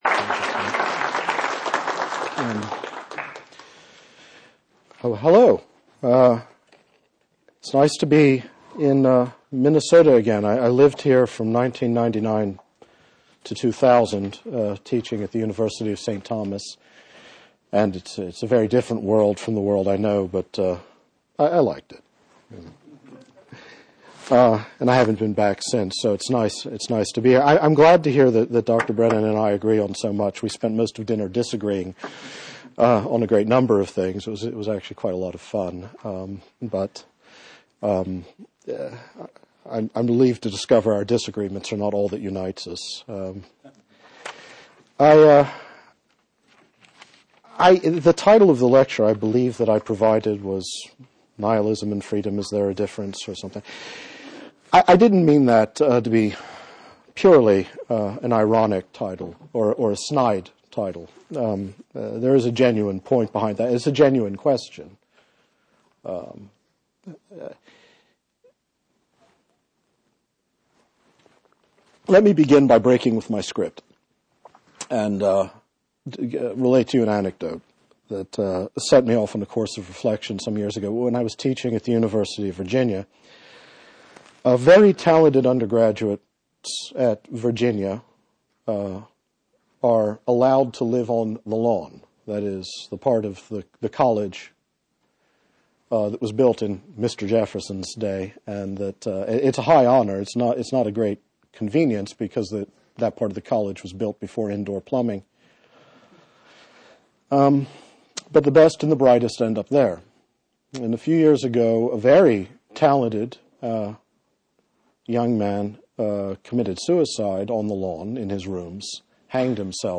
David Bentley Hart, Nihilism and Freedom. Lecture; date unknown. Hart gives a historical overview of how Christian views of "God's sovereignty" and "human freedom" changed over time.